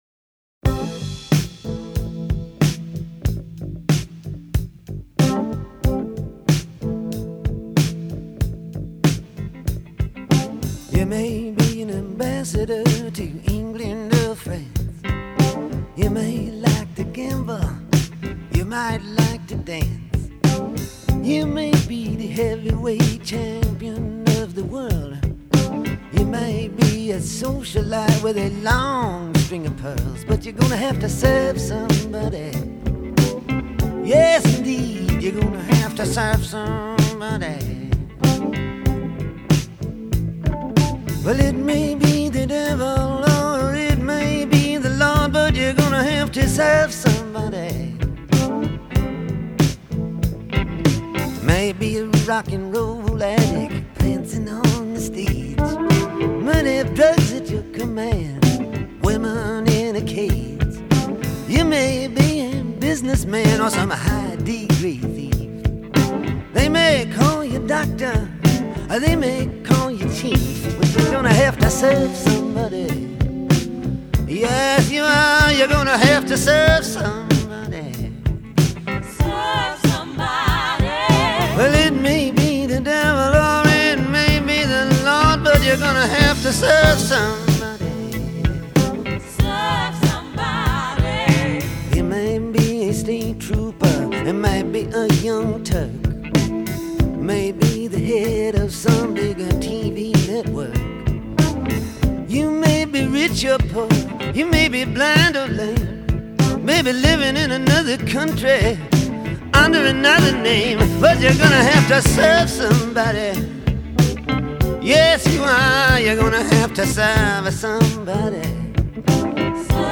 evangelist and Christian rock musician.
lead guitar